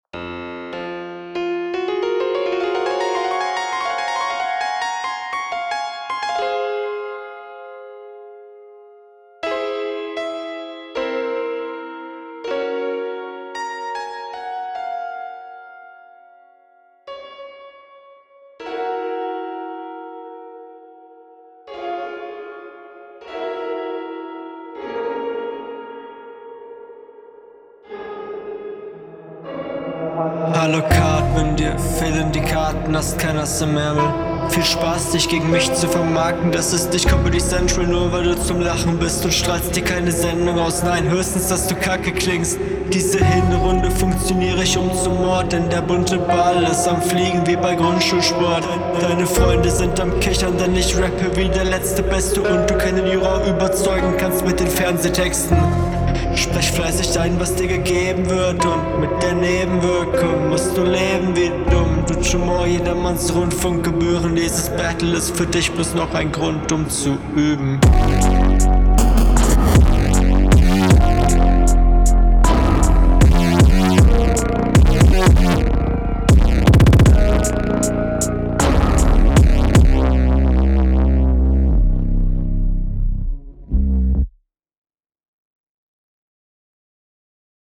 Geiler Beat, sehr kreativ und gut umgesetzt.